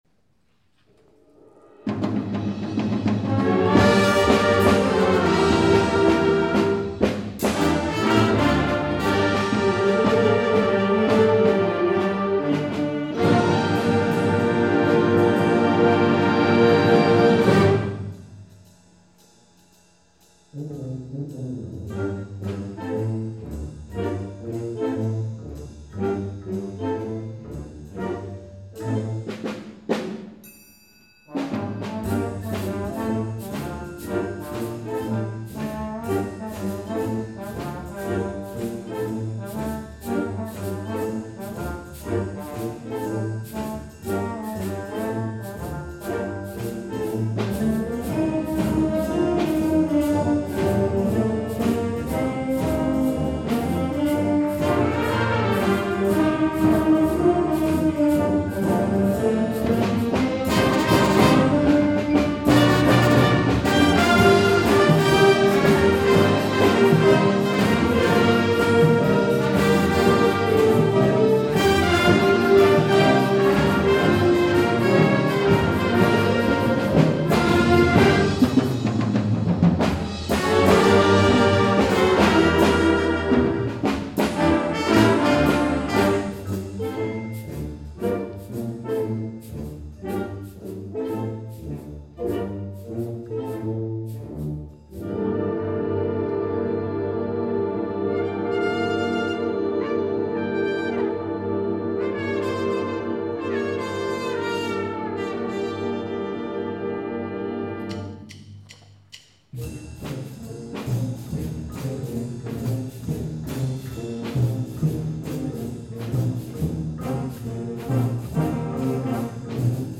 2012 Summer Concert